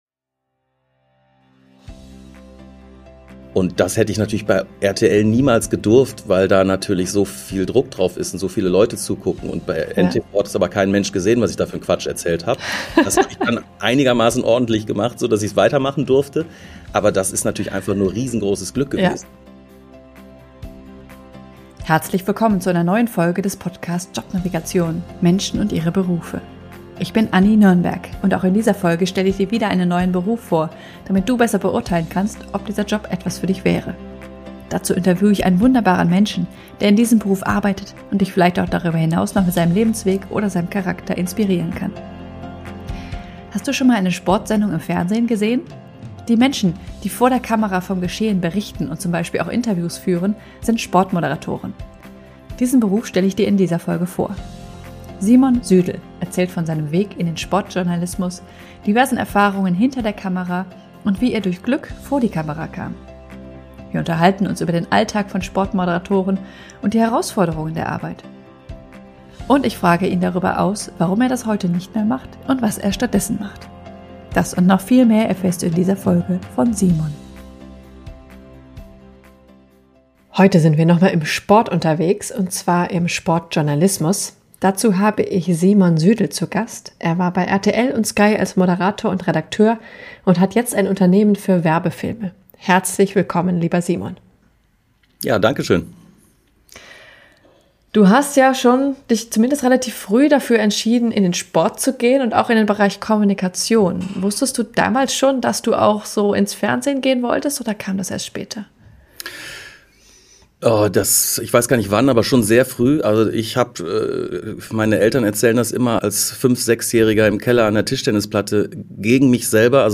Wir unterhalten uns über den Alltag von Sportmoderatoren und die Herausforderungen der Arbeit.